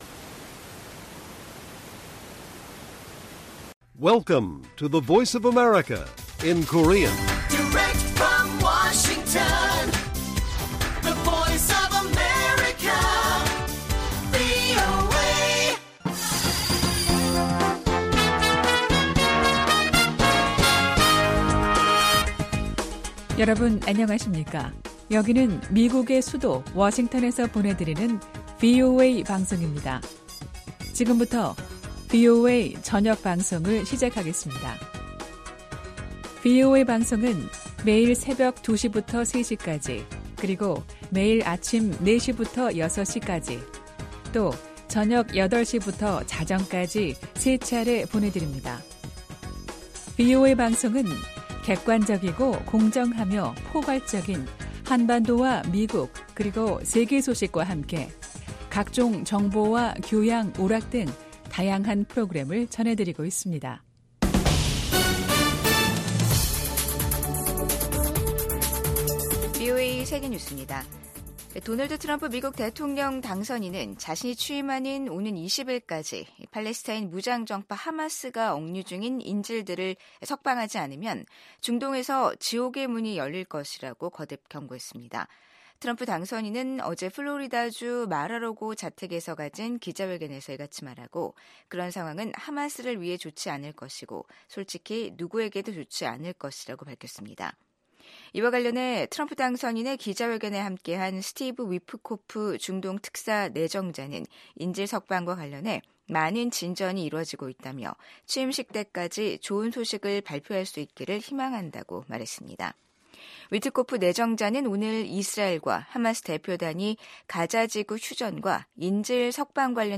VOA 한국어 간판 뉴스 프로그램 '뉴스 투데이', 2025년 1월 8일 1부 방송입니다. 토니 블링컨 국무장관이 미한일 3국 공조가 인도태평양 지역의 안정 유지에 핵심적인 역할을 한다고 평가했습니다. 국제사회가 북한의 신형 극초음속 중거리 탄도미사일 발사를 유엔 안보리 결의 위반이라며 강력히 비판하고 나선 가운데, 미국의 미사일 전문가는 북한의 이번 미사일 시험 발사가 실패했을 가능성이 높다고 진단했습니다.